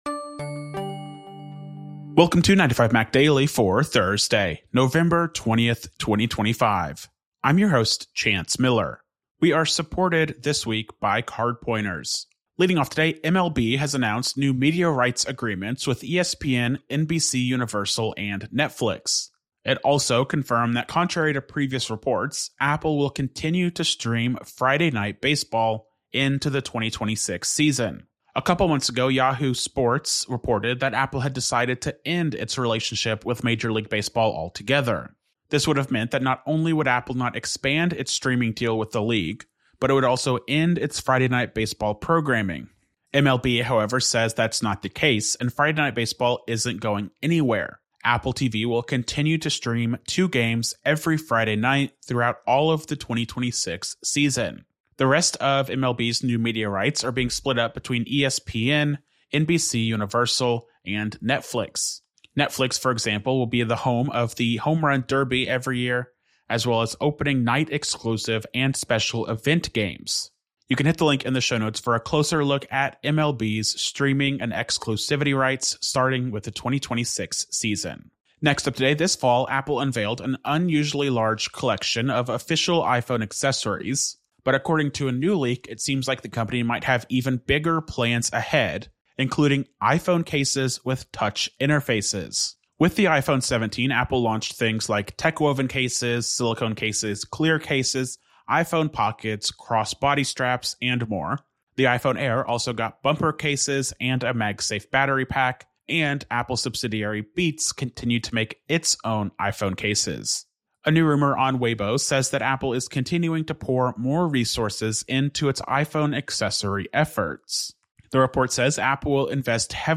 استمع إلى ملخص لأهم أخبار اليوم من 9to5Mac. 9to5Mac يوميا متاح على تطبيق iTunes وApple Podcasts, غرزة, TuneIn, جوجل بلاي، أو من خلال موقعنا تغذية RSS مخصصة لـ Overcast ومشغلات البودكاست الأخرى.